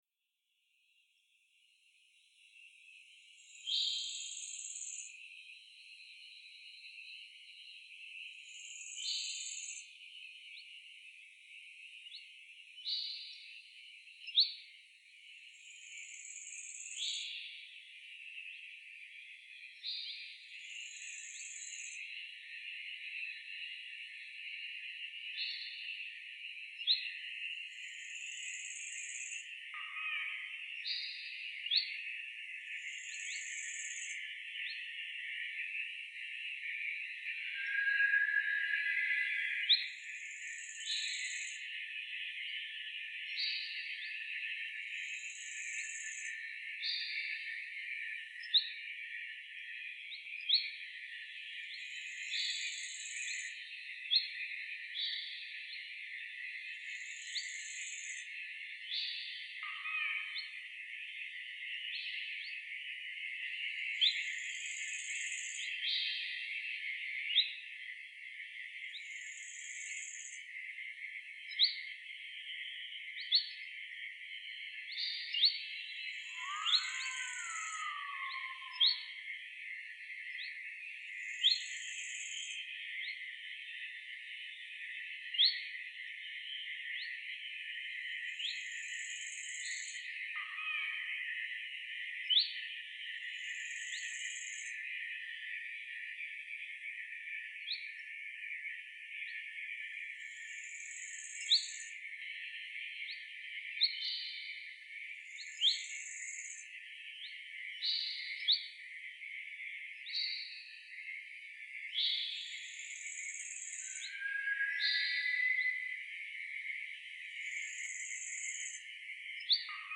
All the distinctive sounds in the original recording have been used and reimagined - a whistle is a bird call, a baby is the "Akhaw" bird, etc.